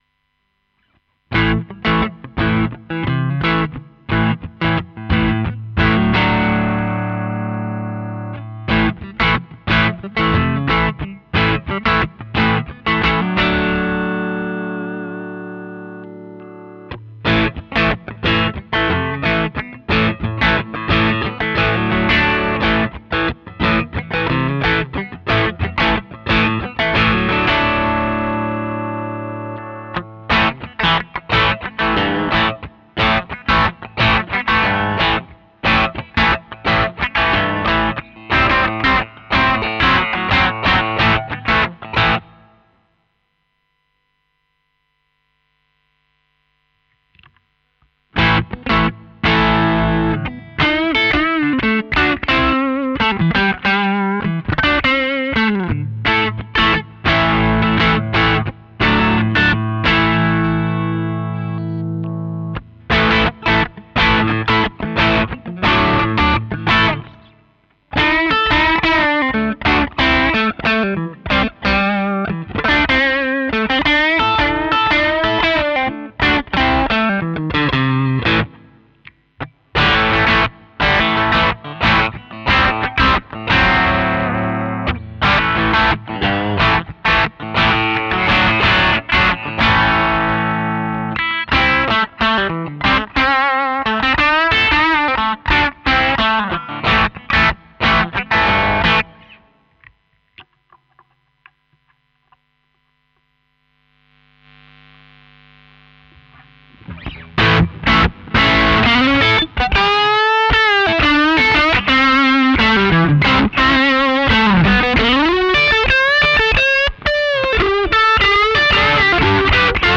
Rock Hard - We have eliminated all the treble buzz and what's left is a great distortion!
We've taken the 80's metal out of the pedal and made it a sweet tone machine. Fat sound, well controlled top end, a good midrange and no more fizzy distortion.
The result is an amazing sounding pedal with a '70's hard rock edge.
Rock Hard Demo 1